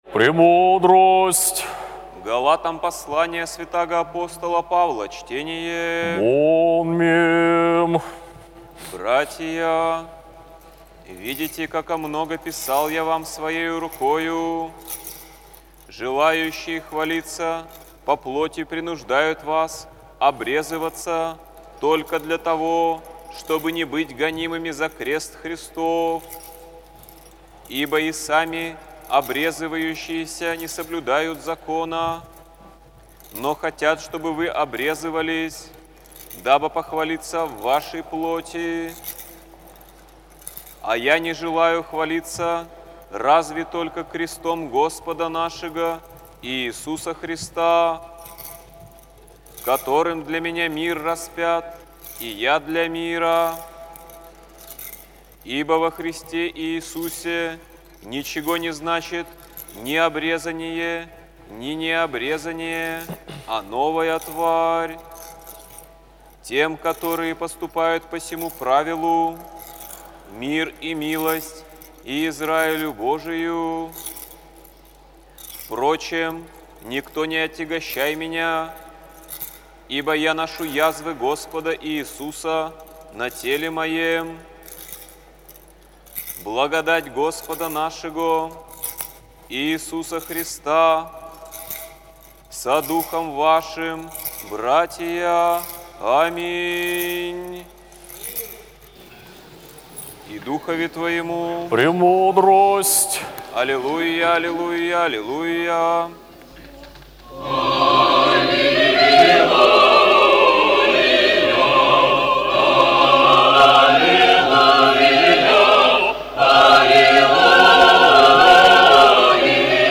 апостольское ЧТЕНИЕ НА ЛИТУРГИИ